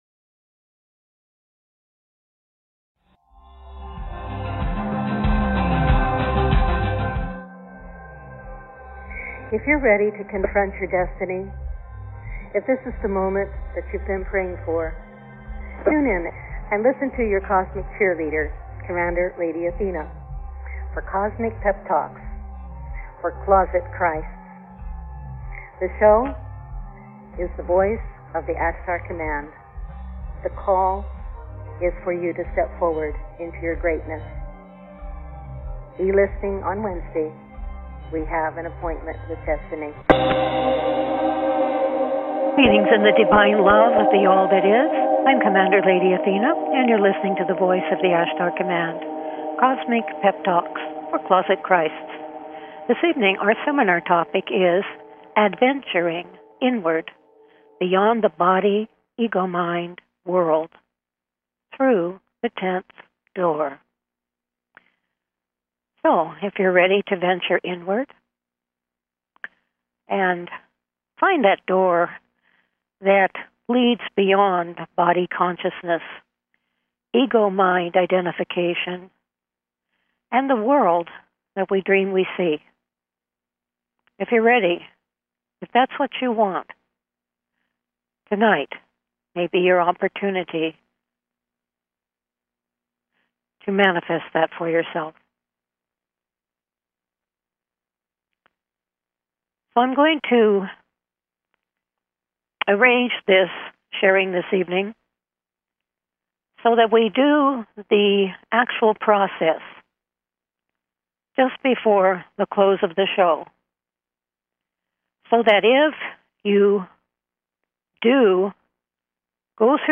Various experiential processes, meditations and teachings evoke your Divine knowing and Identity, drawing you into deeper communion with the All That Is.